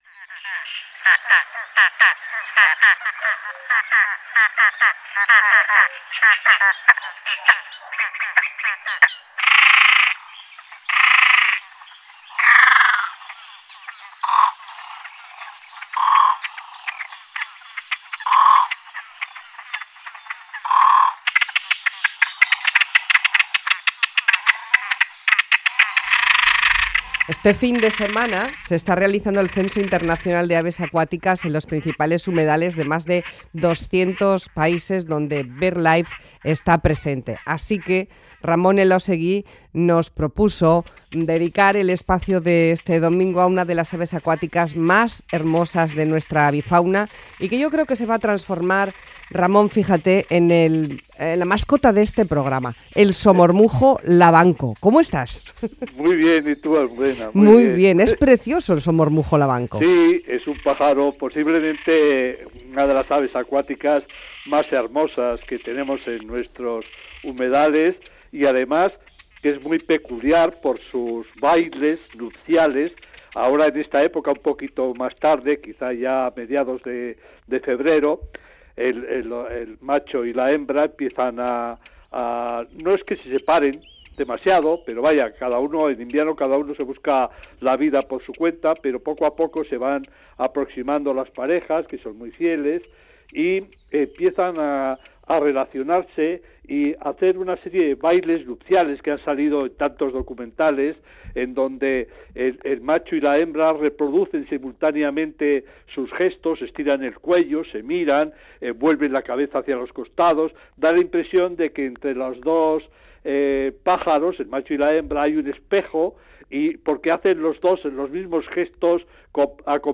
Somormujo lavanco